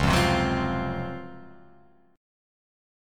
C#M11 chord